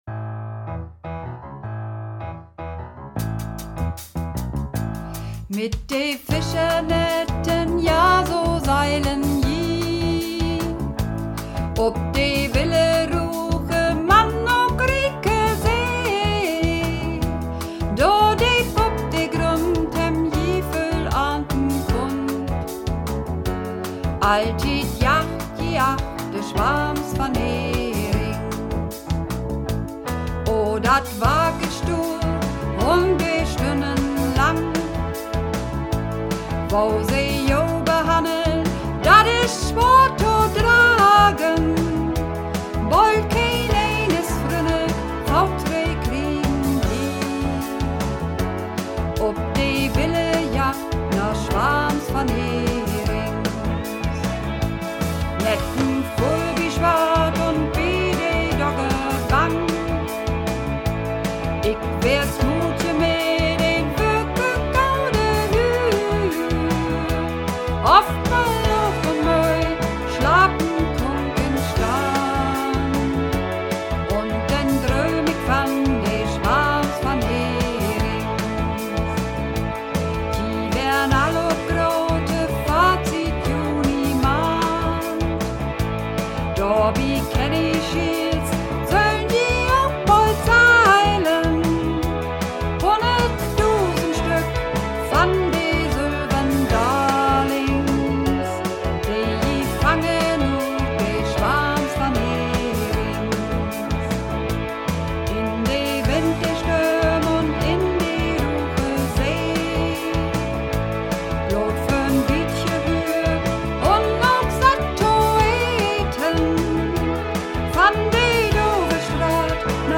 Übungsaufnahmen - Swarms van Herings
Swarms van Herings (Sopran und Männer)